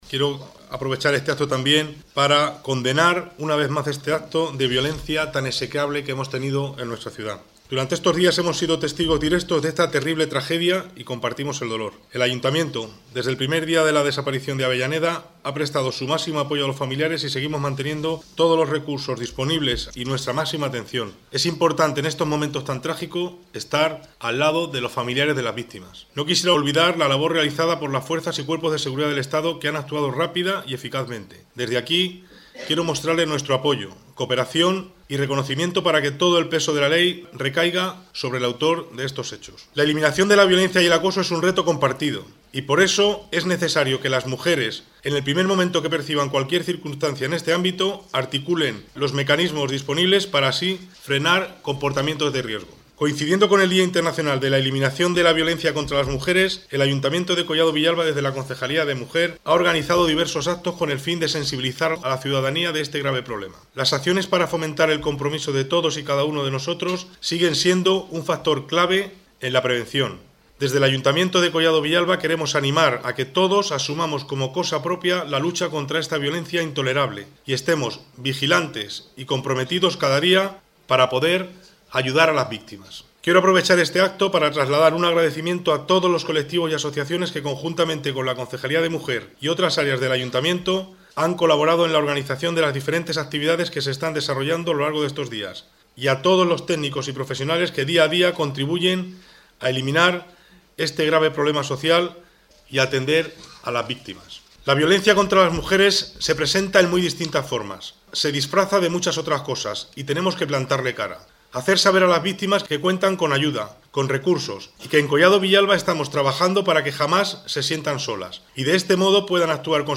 discurso-violencia-de-genero.mp3